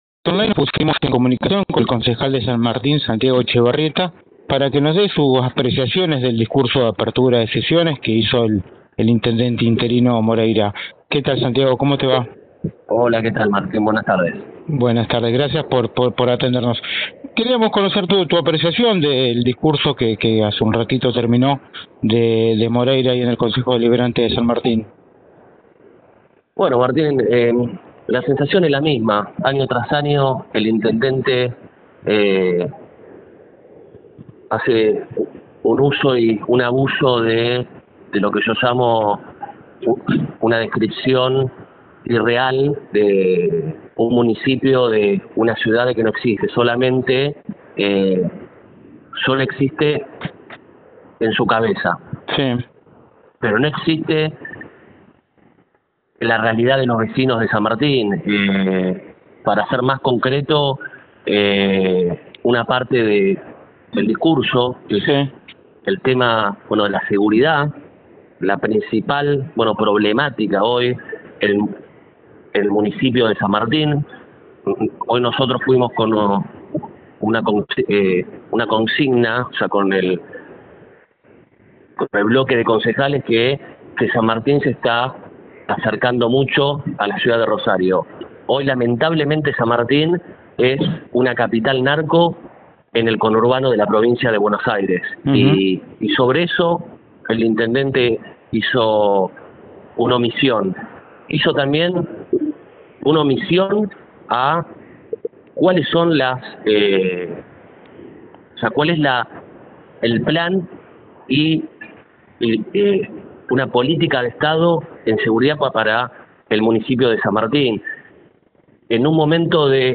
El concejal de Juntos por el Cambio en el distrito habló en exclusiva con NorteOnline y criticó severamente la gestión del intendente, Fernando Moreira.